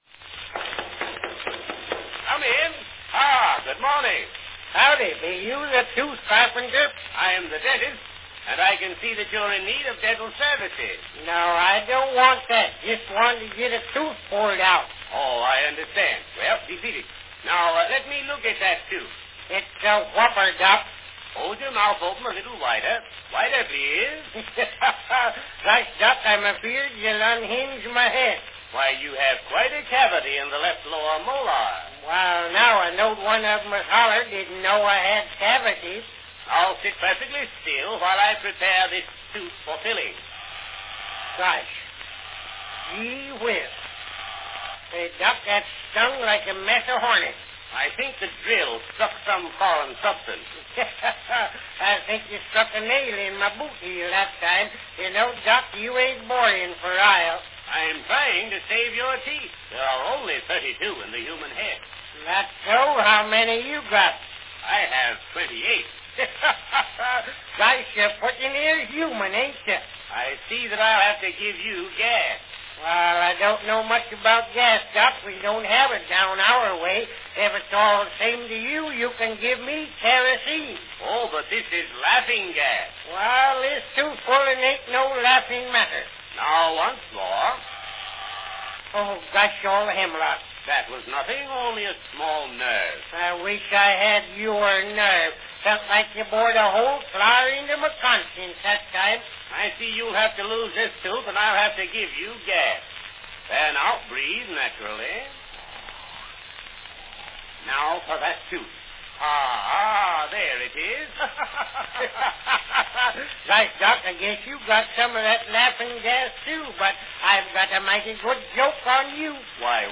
Cal Stewart as Uncle Josh extracts humor in his 1909 skit Uncle Josh at the Dentist's.
Cylinder # 10131
Category Talking
Performed by Cal Stewart & Len Spencer
Cal Stewart's famous Uncle Josh character grew from primarily monolog specialties through the 1890's into more collaborative skits in the 1900's.   Here Len Spencer and sound effects accompany Uncle Josh's oral misadventure.
Another of Mr. Stewart's original Rube sketches.